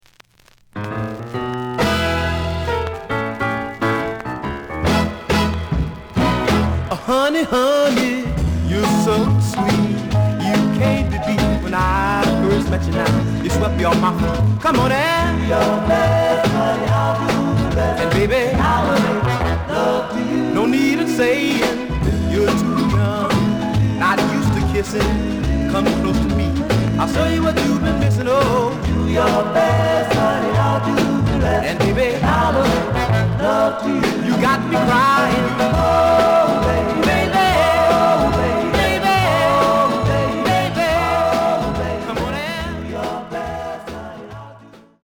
The listen sample is recorded from the actual item.
●Genre: Soul, 60's Soul
Slight edge warp.